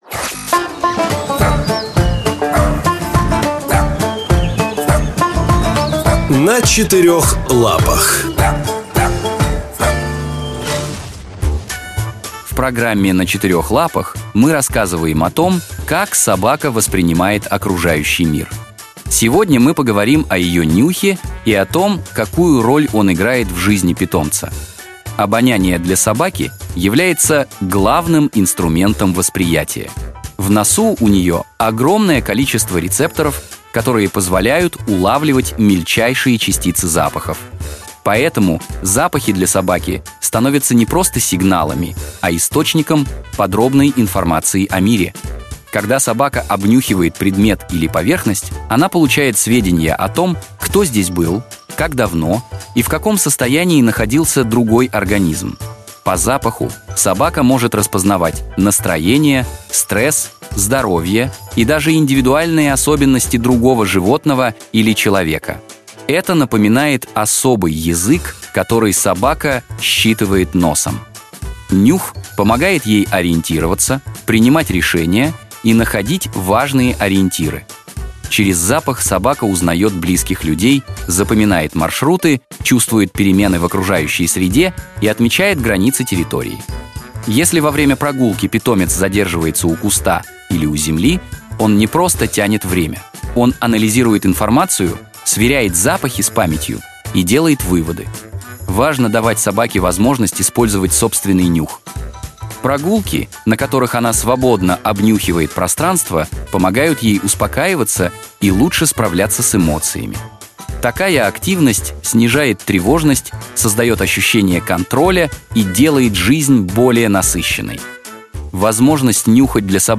Короткие аудиозарисовки о том, как собаки чувствуют, думают и общаются с человеком.